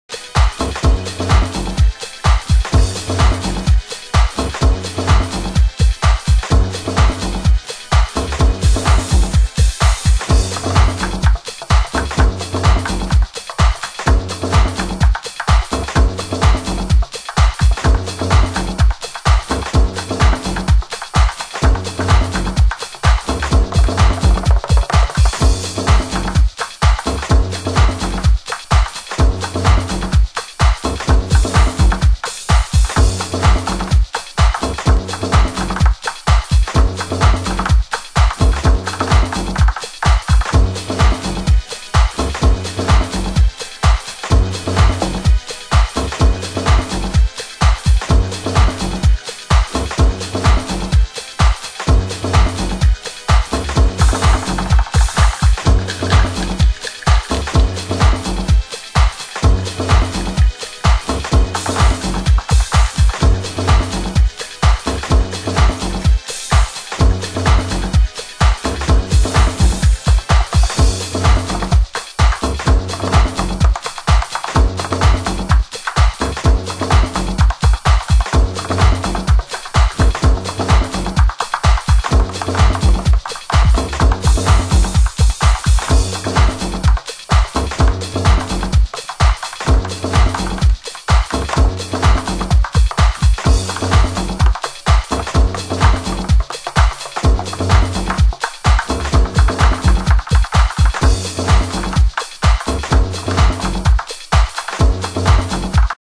ハウス専門店KENTRECORD（ケントレコード）
盤質：少しチリパチノイズ有